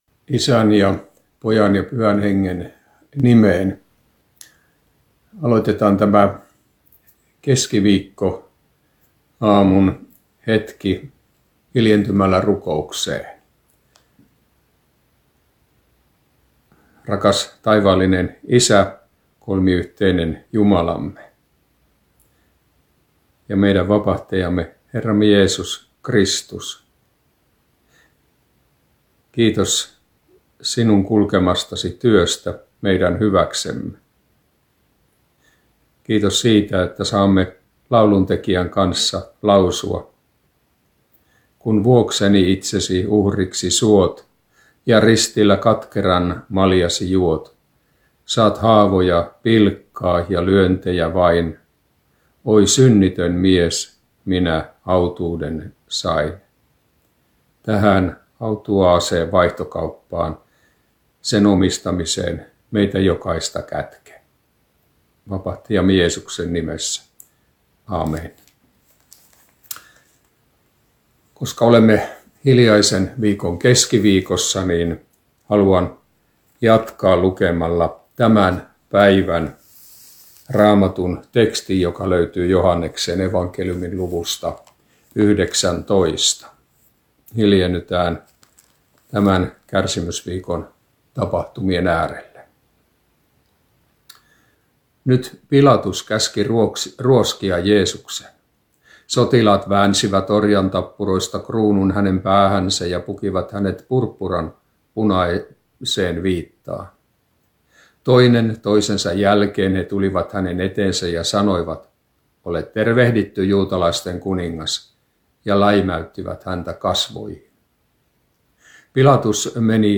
nettiaamuhartaus Kokkolassa hiljaisen viikon keskiviikkona Tekstinä Joh. 19: 1-16